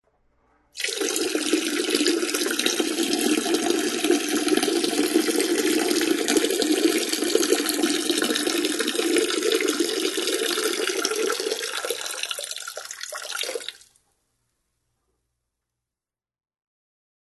На этой странице собраны различные звуки мочеиспускания в высоком качестве.
Звук падающей струи мочи